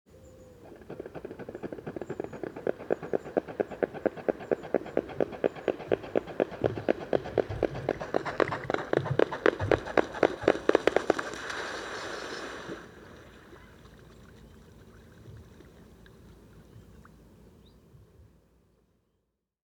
Bütykös hattyú (Cygnus olor) hangja
Hangjai általában rekedtesek, mély tónusúak, és kevésbé csengőek, mint más vízimadaraké.
A leggyakoribb a rekedtes, mély torokhang, amely rövid, fújtató vagy mordulásszerű formában hallatszik.
Bár nem éneklőmadár, különféle rekedtes, fújtató, morduló és sziszegő hangokat használ, amelyeket kiegészít a repülés közben hallható szárnysuhogás.